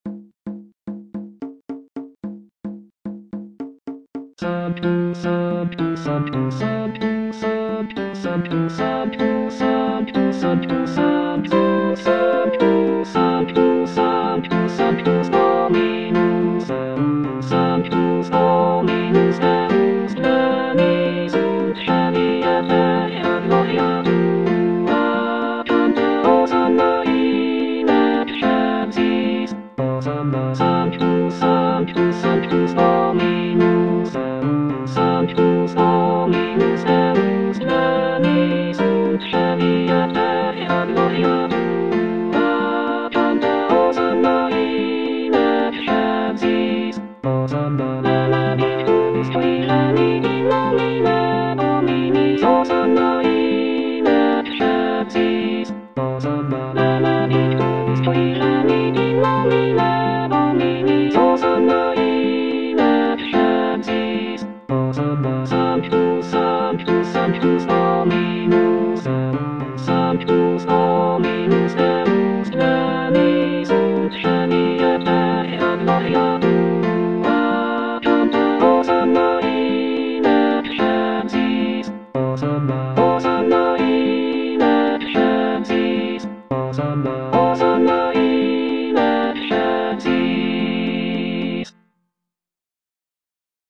(All voices) Ads stop